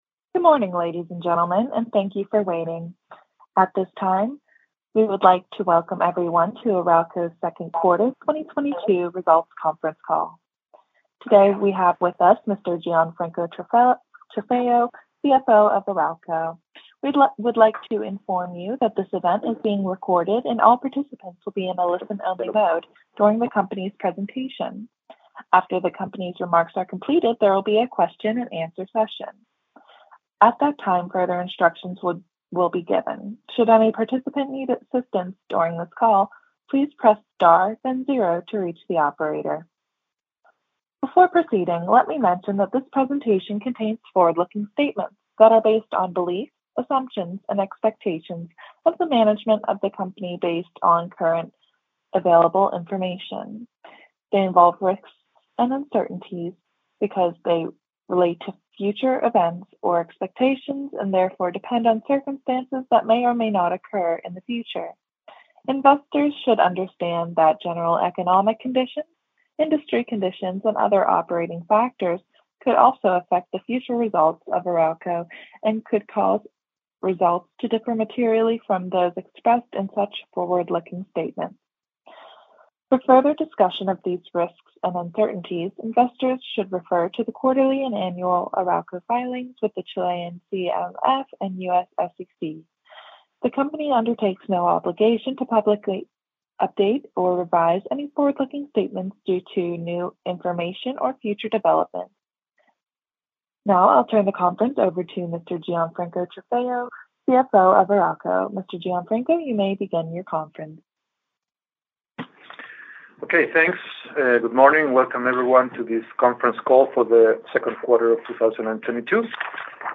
Conference Call Audio 2Q2022